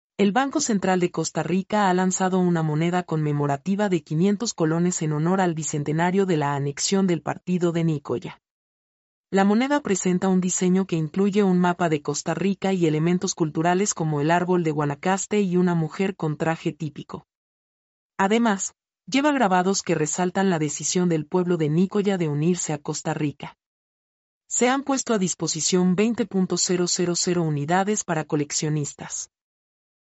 mp3-output-ttsfreedotcom-40-1.mp3